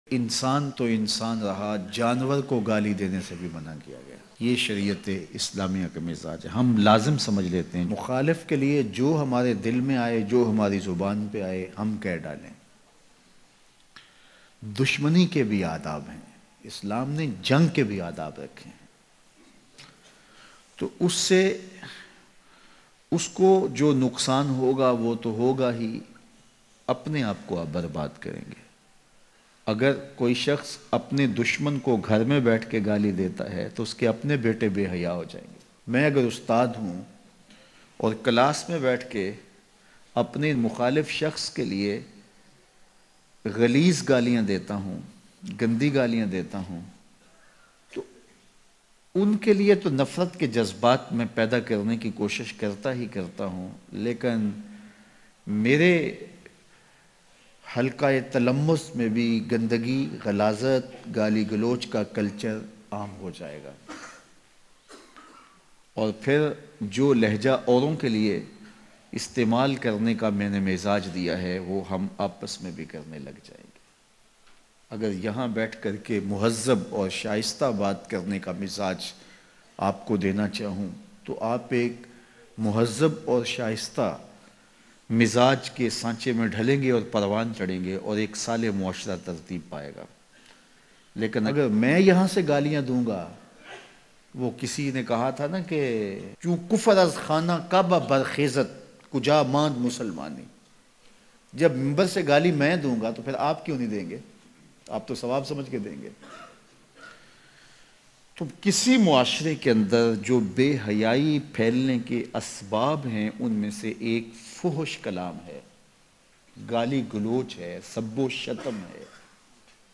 Lafzo Ka Parda Bayan